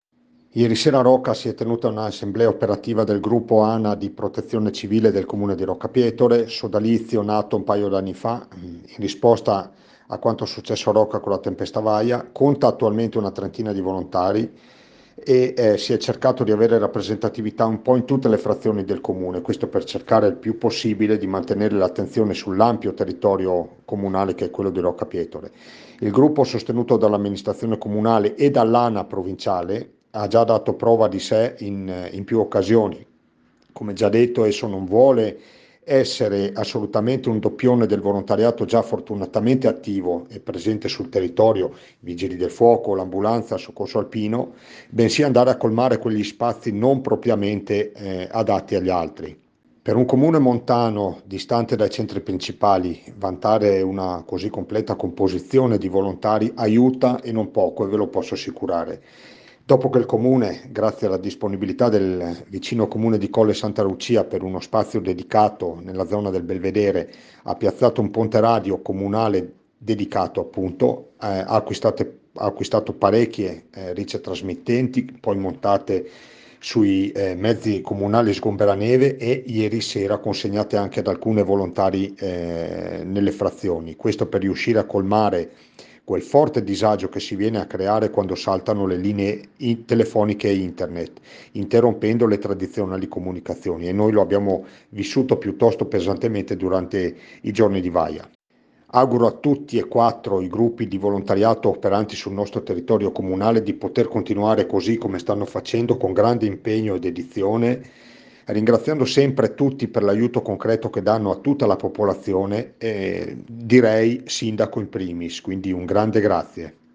IL SINDACO DI ROCCA PIETORE ANDREA DE BERNARDIN